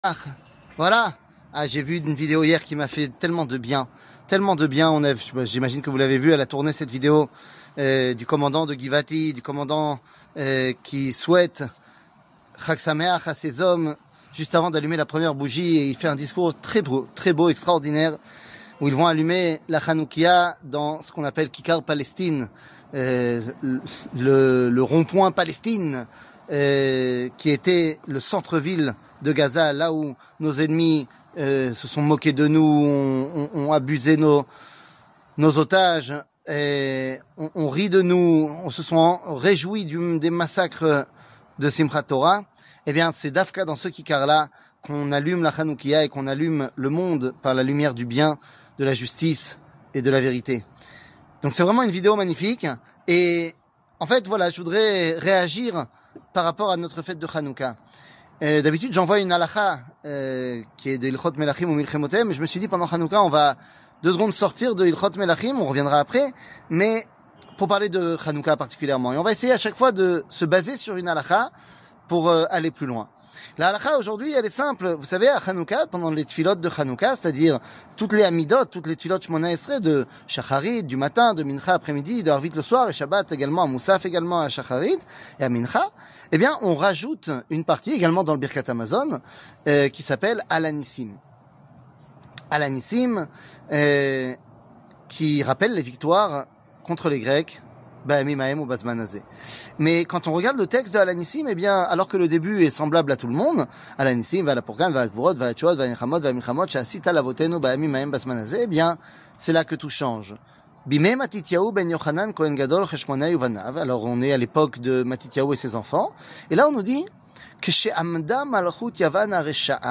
קטגוריה Special Hanouka 1 00:05:15 Special Hanouka 1 שיעור מ 10 דצמבר 2023 05MIN הורדה בקובץ אודיו MP3 (4.79 Mo) הורדה בקובץ וידאו MP4 (10.88 Mo) TAGS : שיעורים קצרים ראה גם רוצים לתרום לעמותה של הרב ?